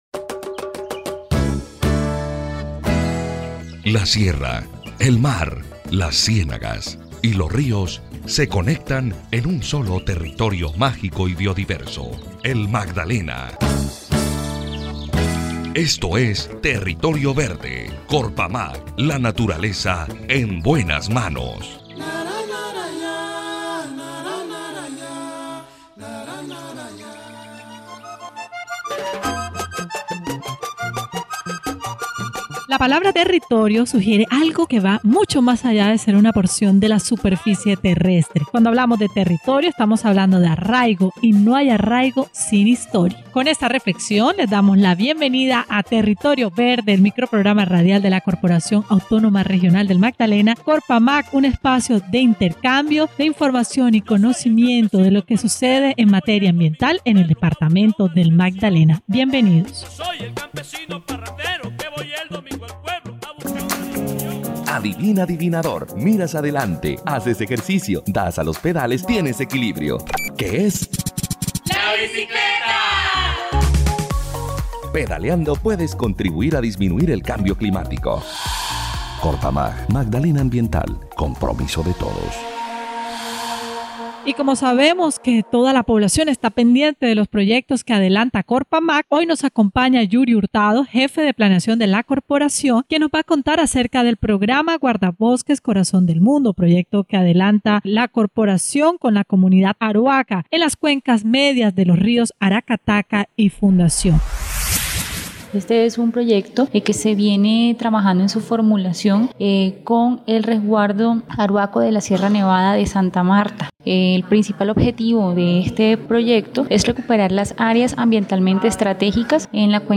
Expertos de la entidad explicaron qué es lo que viene haciendo en el programa radial Territorio Verde: